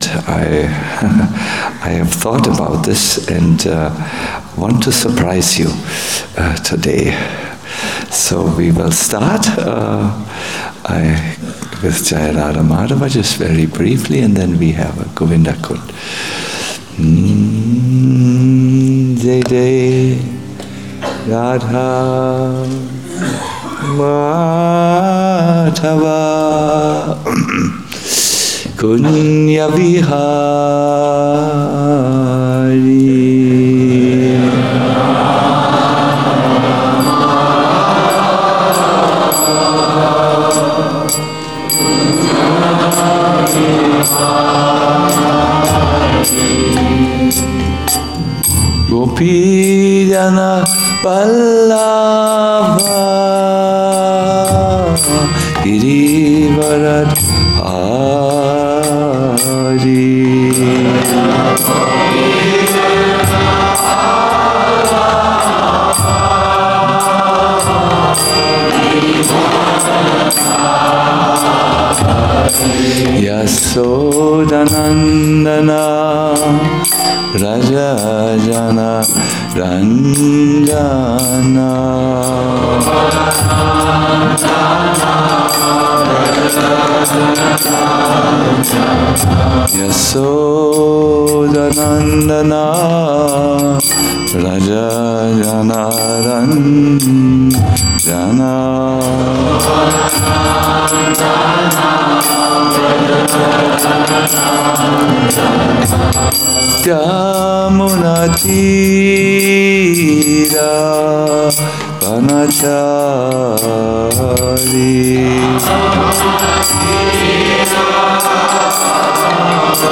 Govardhana Retreat Vraja Vilasa Stavah 5 - Who is who in Krsnas world - a lecture
Govardhana Retreat Center